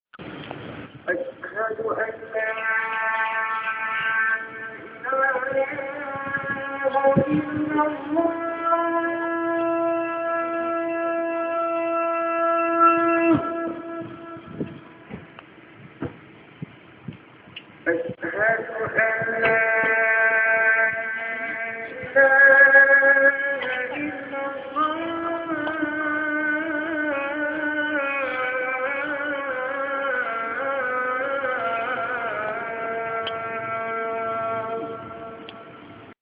E basta un cellulare, se non si ha un registratore professionale.
Arab Street, Singapura, ore 17.